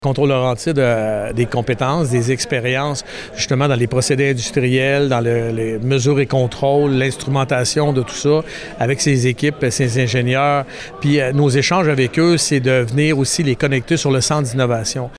Contrôles Laurentide, un fournisseur de solutions d’automatisation a annoncé jeudi en conférence de presse, la construction d’un nouveau centre dans la zone entrepreneuriale à Bécancour.